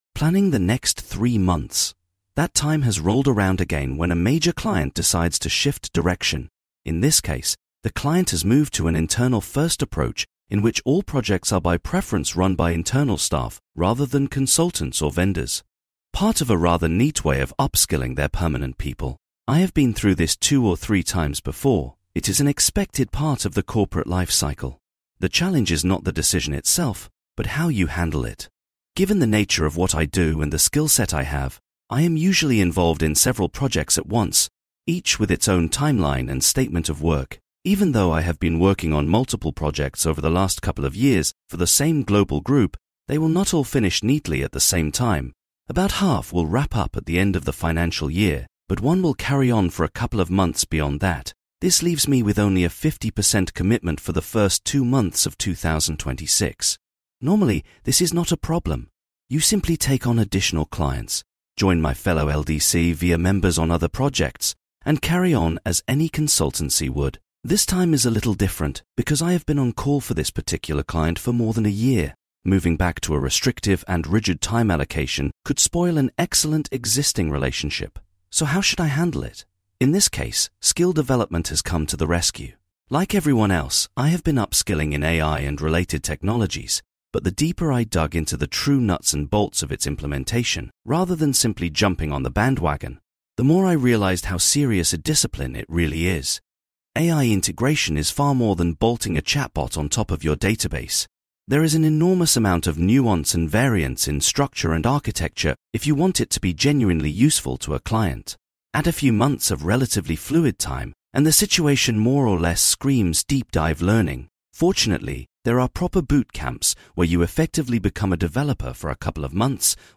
I use one both to ensure all my blog posts have an audio version and because, as someone who is badly dyslexic, I find it the best way of proofreading my work.